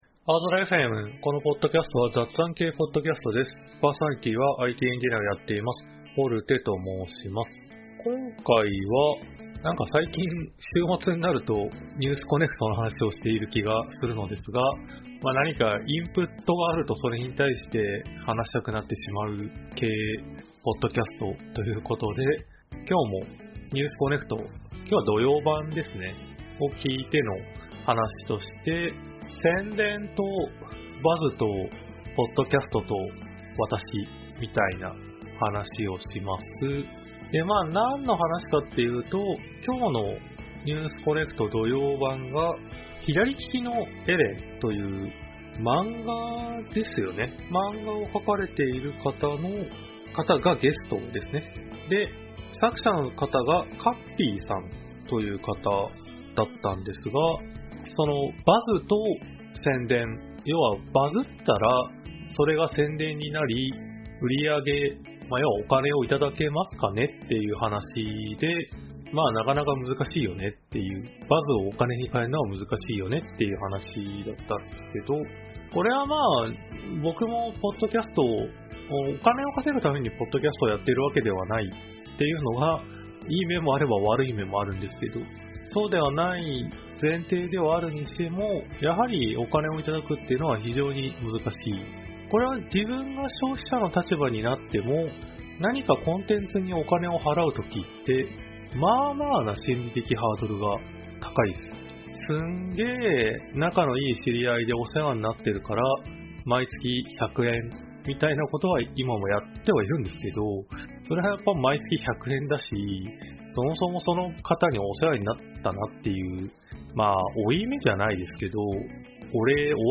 aozora.fmは仕事や趣味の楽しさを共有する雑談系Podcastです。